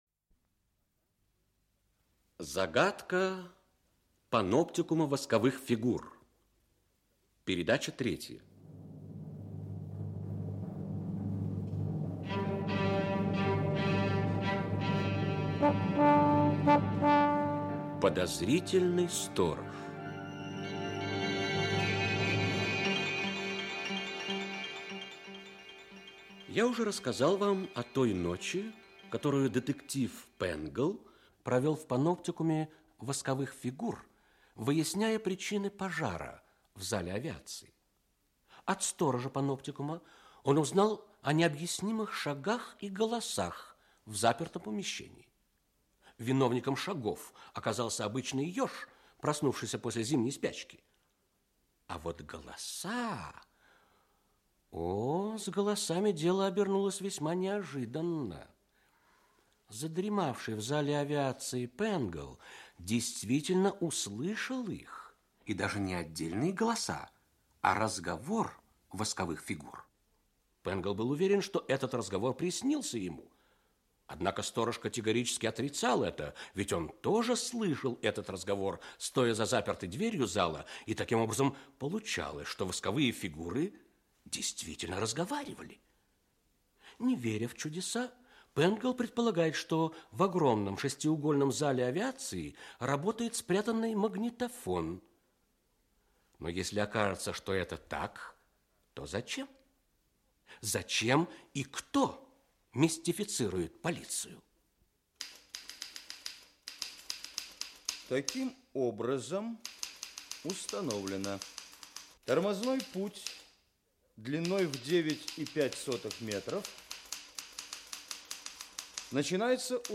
Аудиокнига Загадка паноптикума восковых фигур. Часть 3. Подозрительный сторож | Библиотека аудиокниг